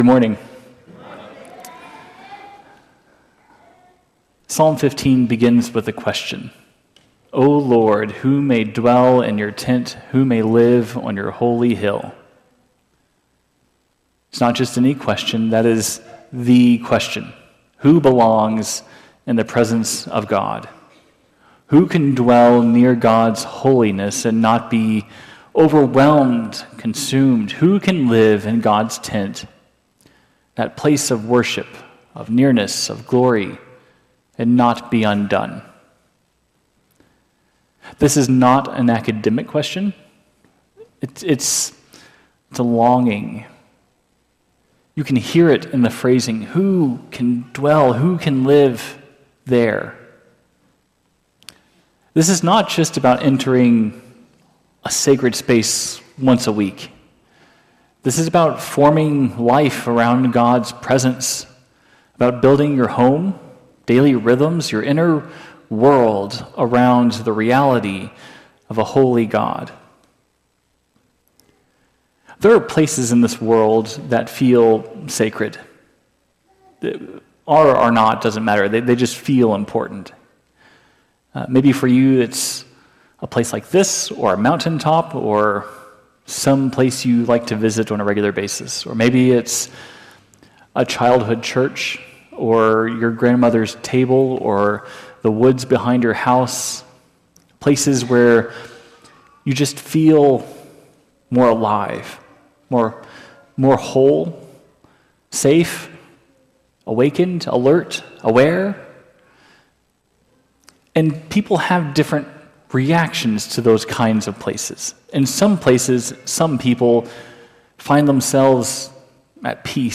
This sermon challenges believers to embody faithful living rooted in God’s presence.